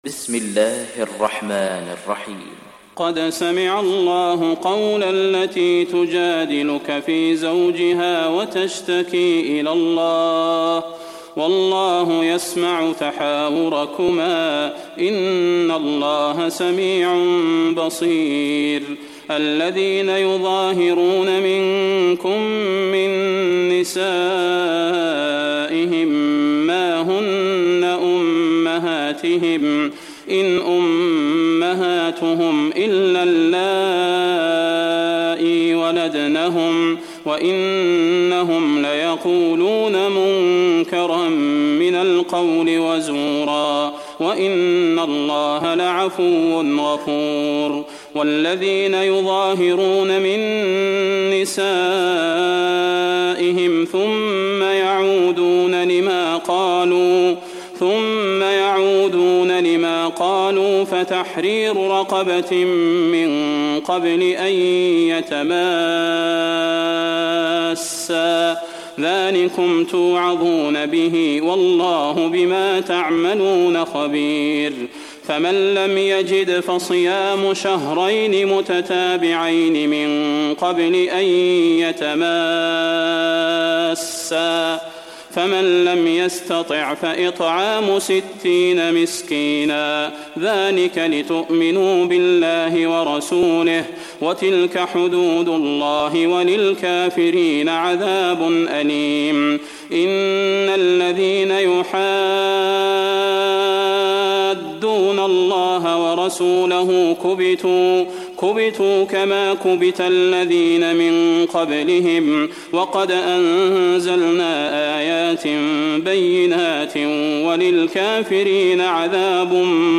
تحميل سورة المجادلة mp3 بصوت صلاح البدير برواية حفص عن عاصم, تحميل استماع القرآن الكريم على الجوال mp3 كاملا بروابط مباشرة وسريعة